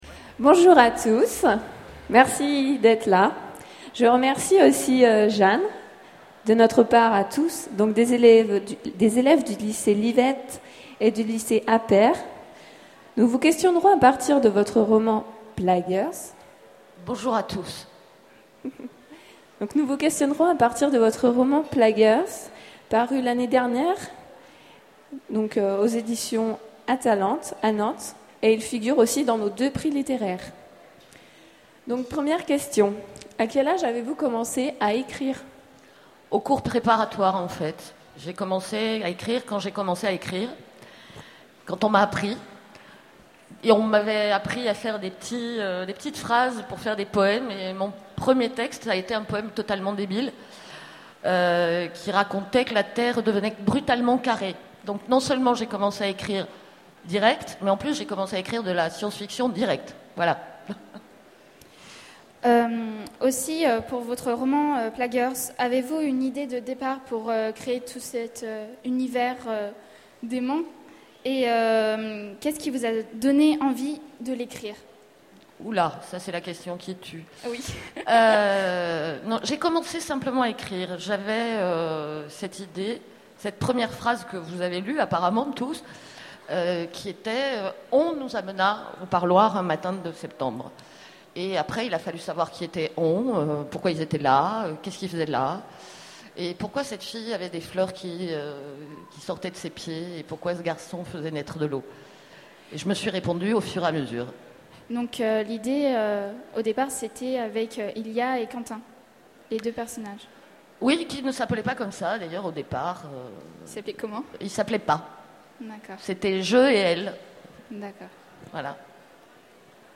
Conférence
Rencontre avec un auteur